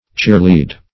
cheerlead - definition of cheerlead - synonyms, pronunciation, spelling from Free Dictionary
cheerlead.mp3